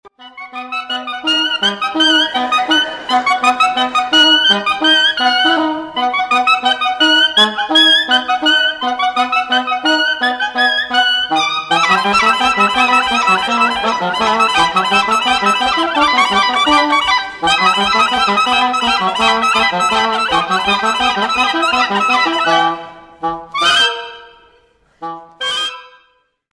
ドイツ製　世界最小、最高音域のピッコロサックス
ソプリロ音源4
soprillo4.mp3